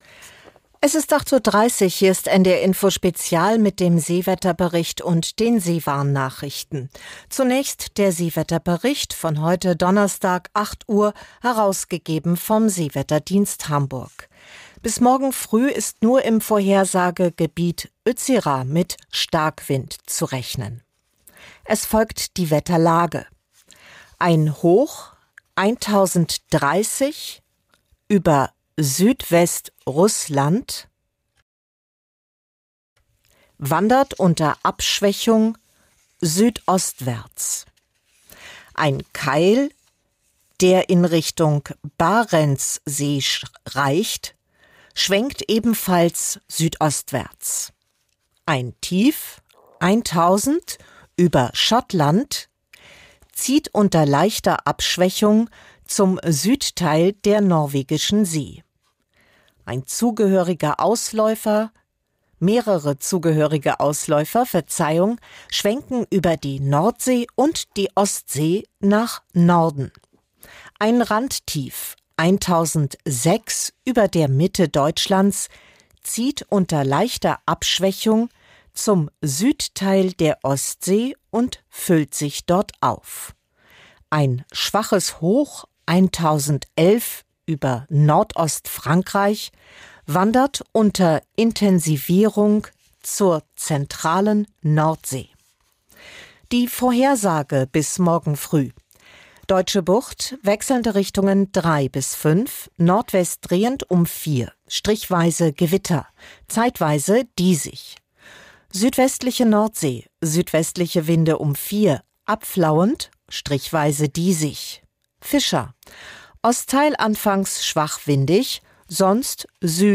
Nachrichten - 04.06.2025